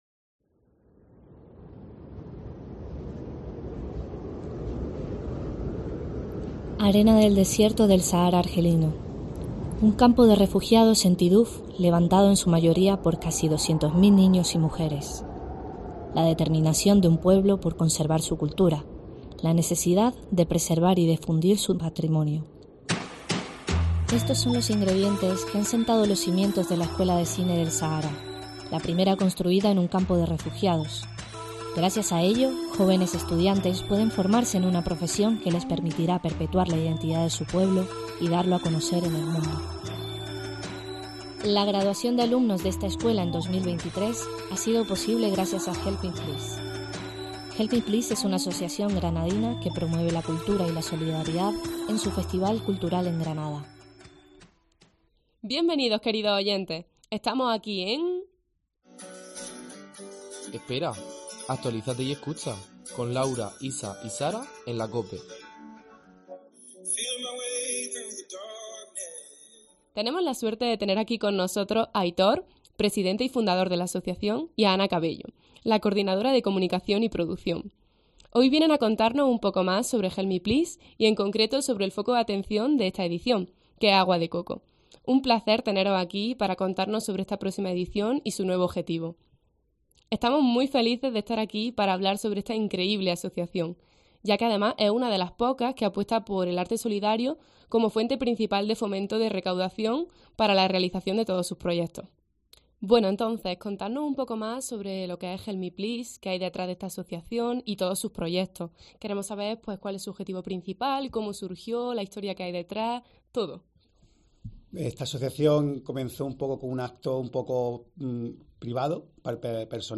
Un reportaje